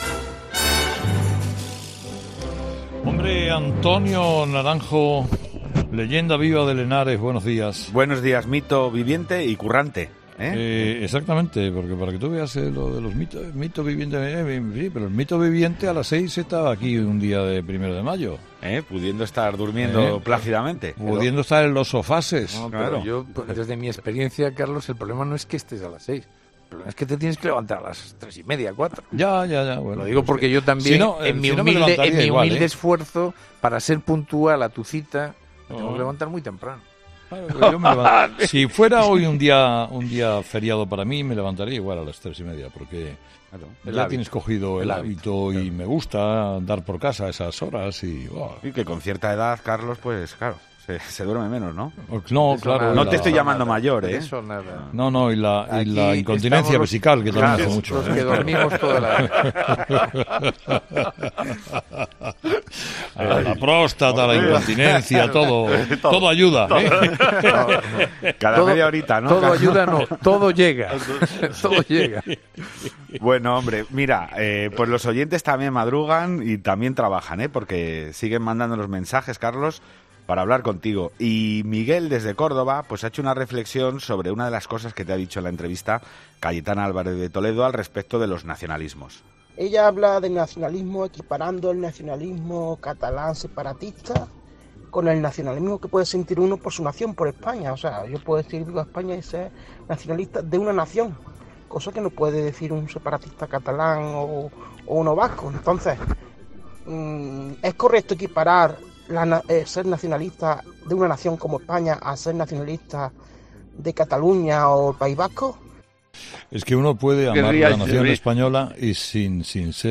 1 de mayo, día del trabajador, y de ello ejerce Carlos Herrera, al frente del programa líder de la radio española.
Muy divertida. Pero Venezuela y la política española no se toman descanso y han provocado un alud de mensajes de la audiencia.